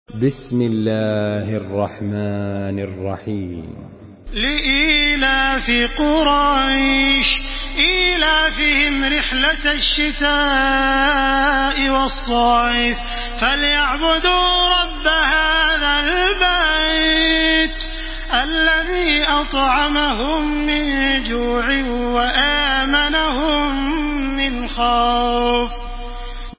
Surah Kureyş MP3 by Makkah Taraweeh 1434 in Hafs An Asim narration.
Murattal